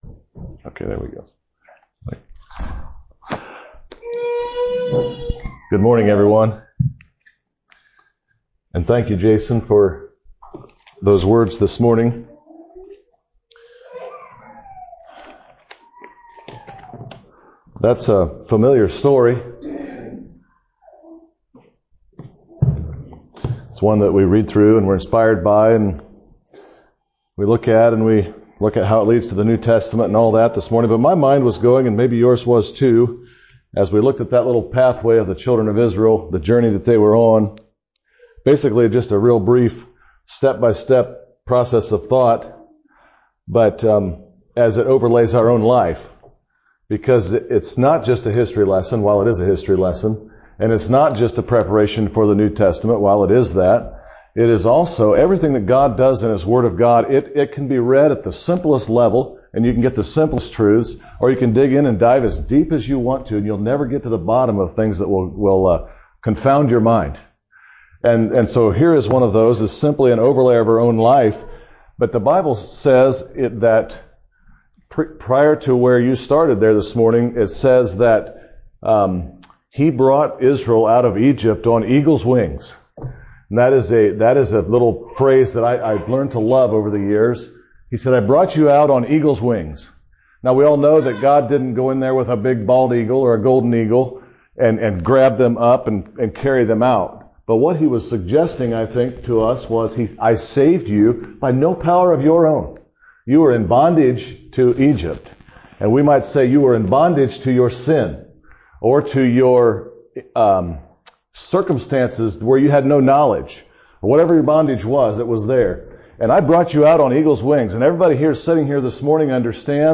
Listen to and download sermons preached in 2025 from Shelbyville Christian Fellowship.